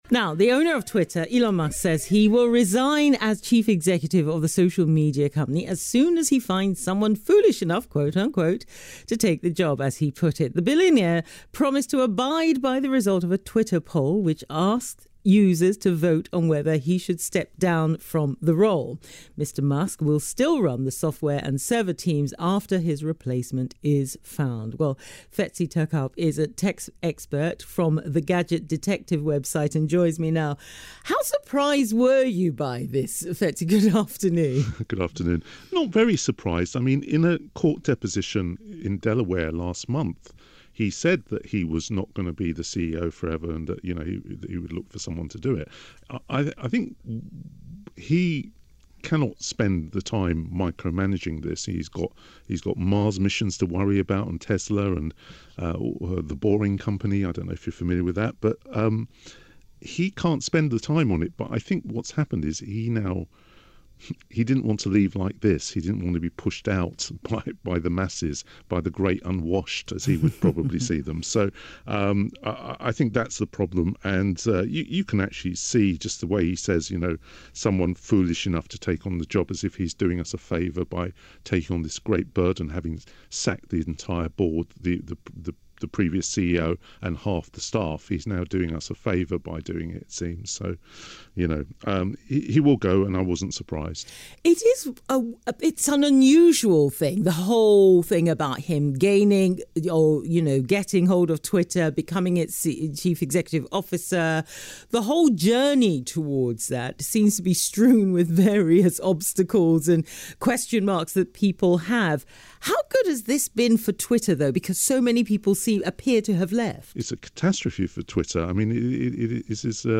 A selection of free tech advice & tech news broadcasts